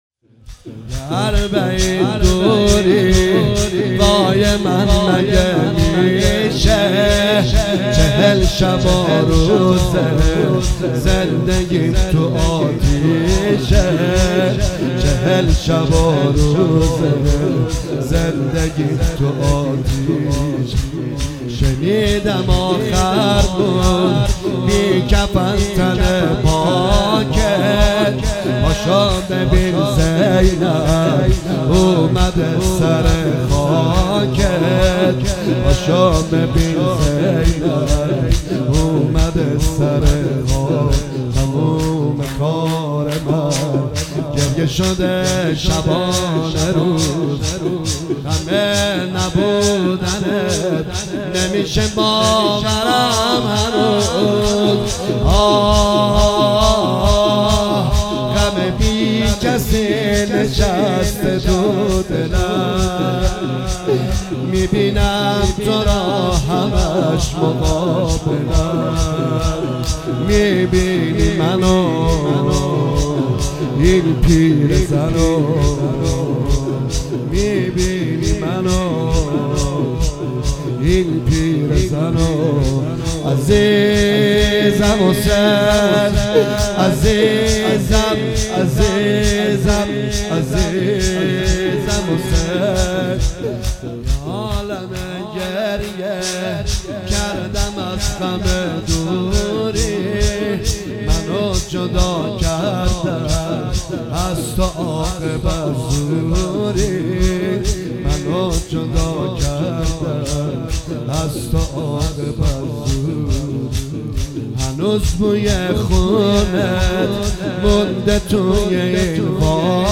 دانلود مداحی دلنشین